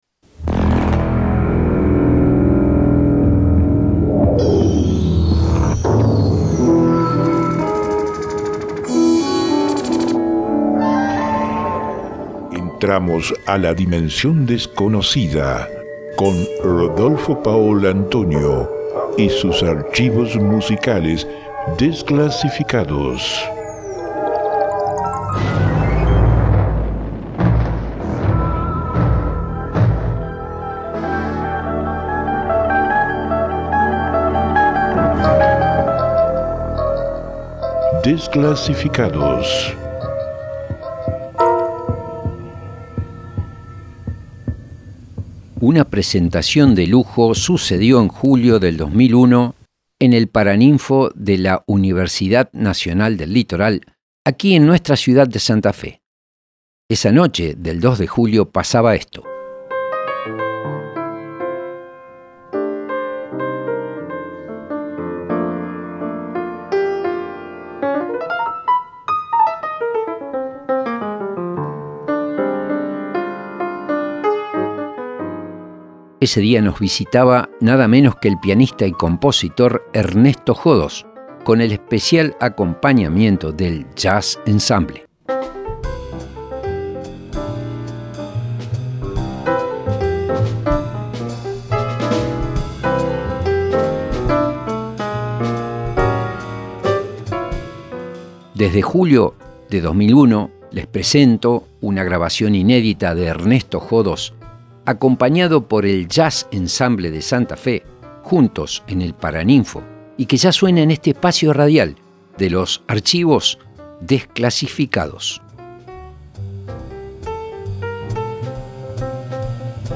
en concierto en el Paraninfo de Santa Fe